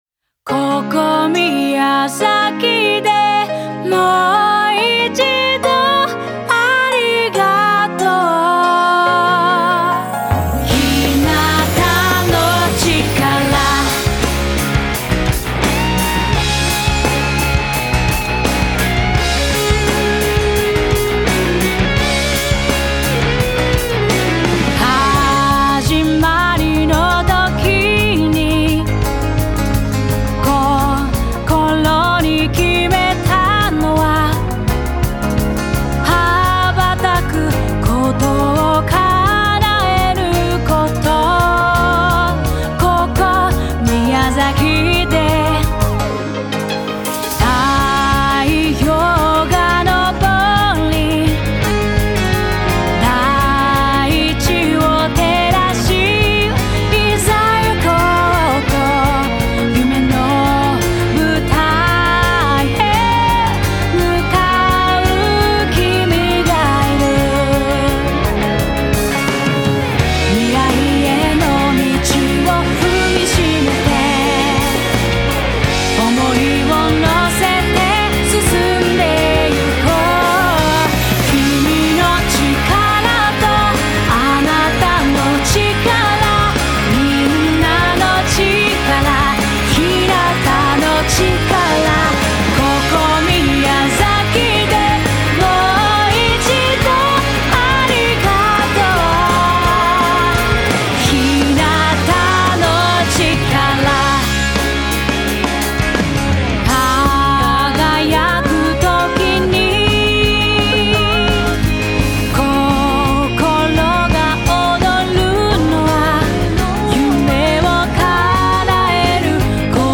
宮崎県ゆかりの3人のアーティストが奏でる大会イメージソング
ノーマルバージョン